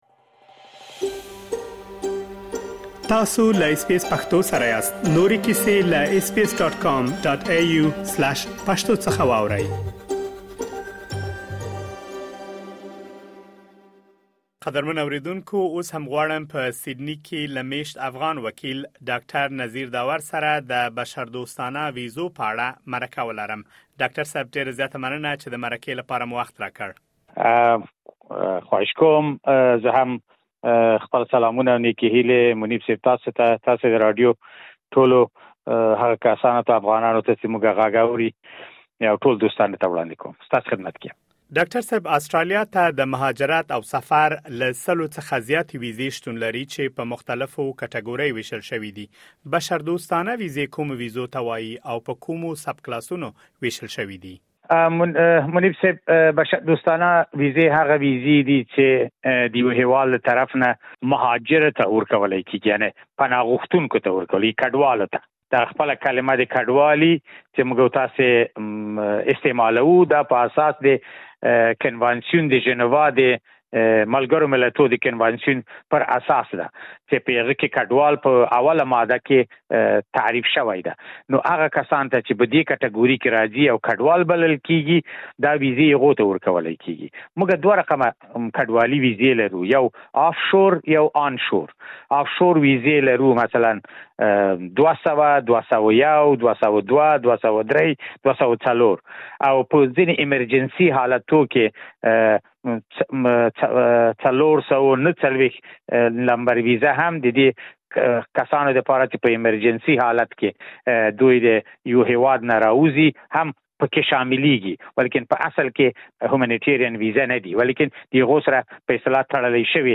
تاسو کولی شئ مهم معلومات دلته په ترسره شوې مرکې کې واورئ.